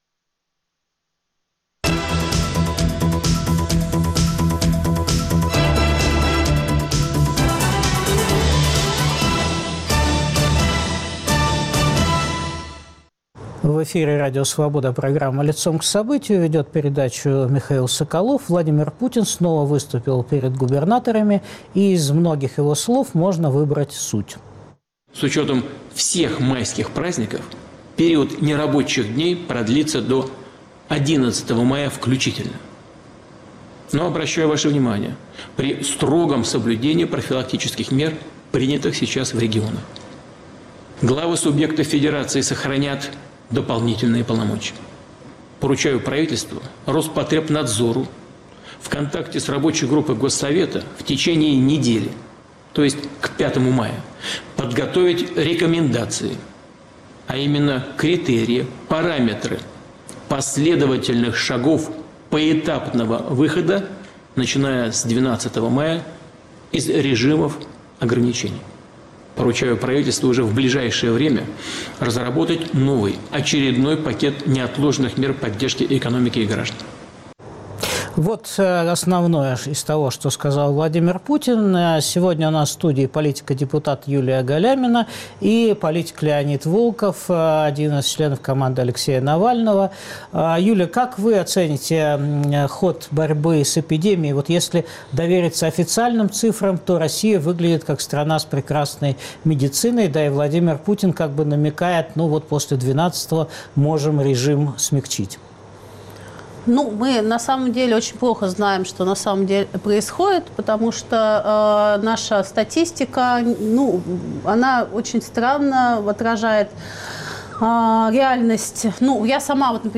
Обсуждают политик из команды Алексея Навального Леонид Волков и муниципальный депутат, один из организаторов митинга «За жизнь!» в YouTube Юлия Галямина.